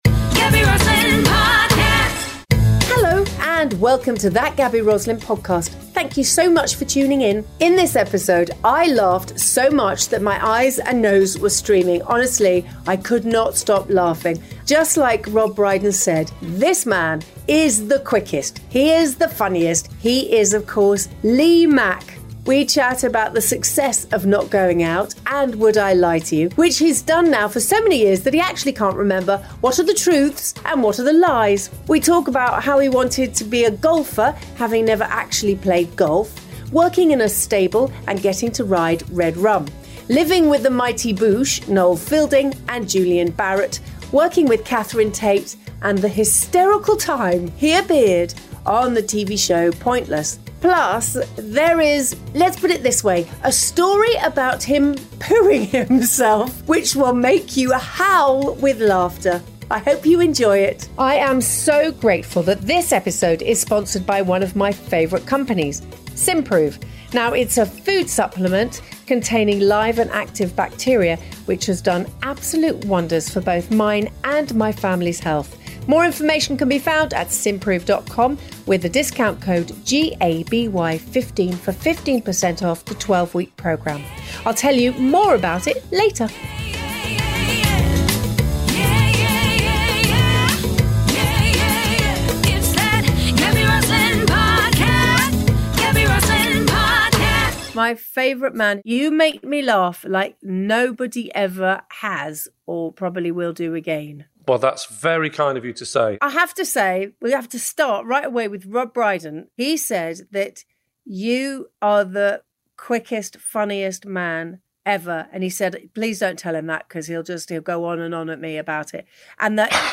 In this episode, Gaby chats and laughs with comedian and actor Lee Mack. They chat about the ongoing success of BBC shows ‘Not Going Out’ and ‘Would I Lie To You’, plus the hilarious story of what happened when he appeared on ‘Pointless’ with Bobby Ball.